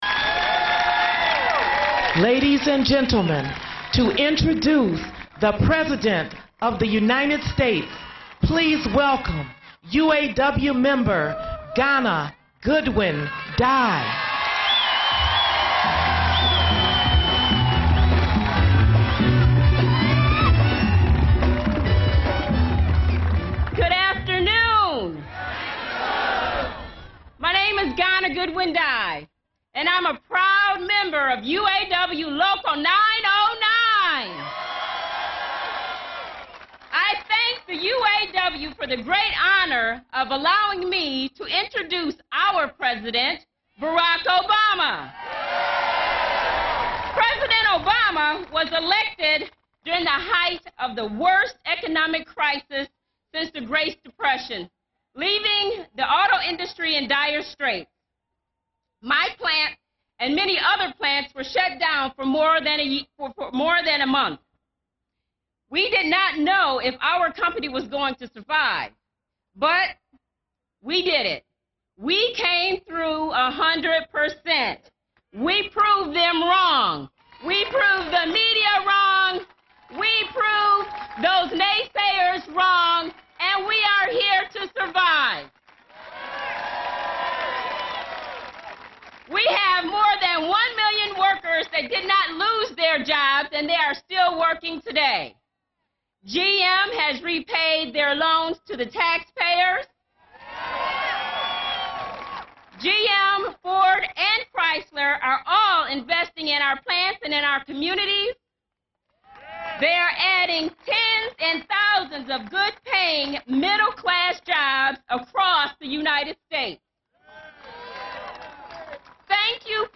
U.S. President Barack Obama speaks at a 2011 Labor Day gathering in Detroit, MI
Broadcast on C-SPAN, Sept. 6, 2011.